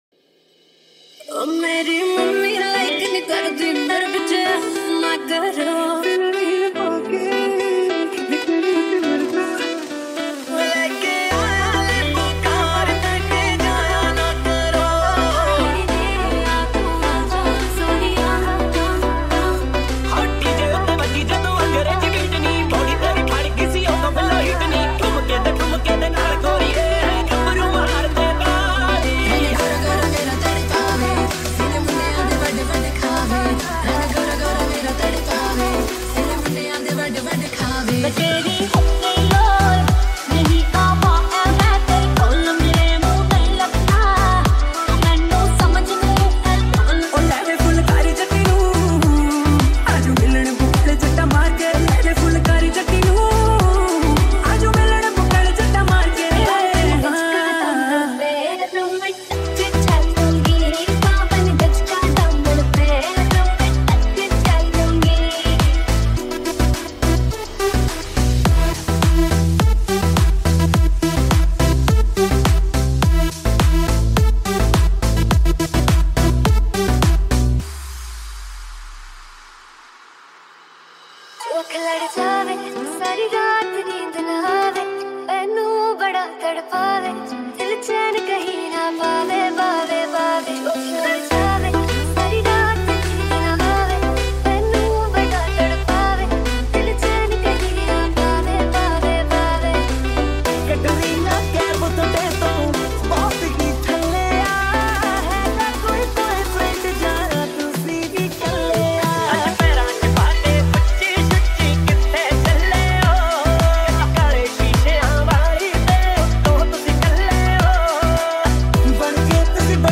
High quality Sri Lankan remix MP3 (3.3).